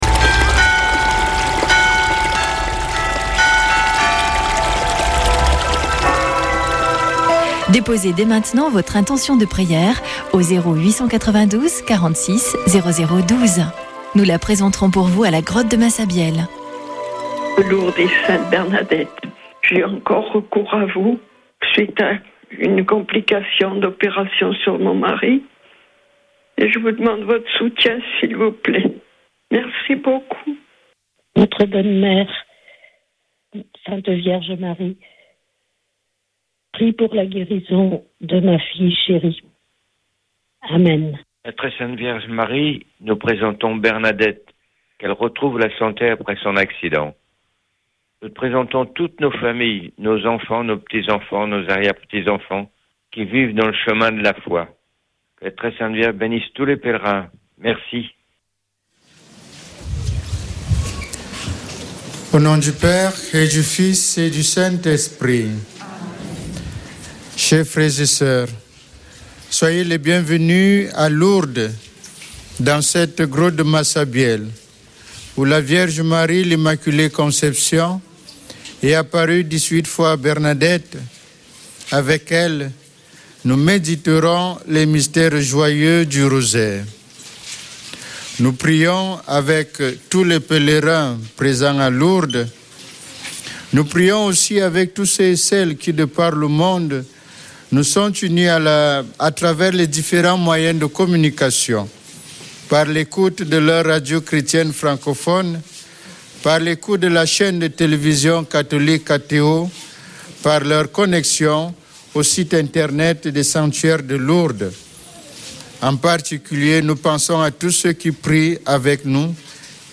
Chapelet de Lourdes du 27 oct.
Une émission présentée par Chapelains de Lourdes